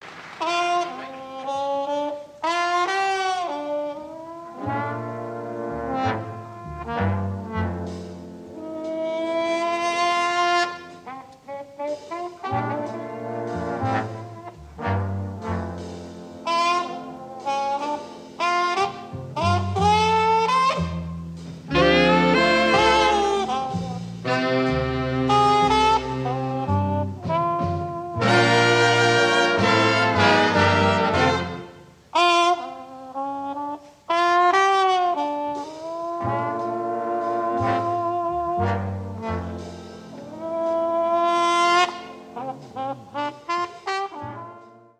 Helsinki 1963